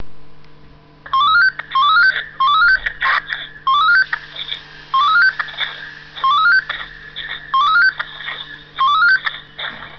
Motorola GM-340 на TX излучает что-то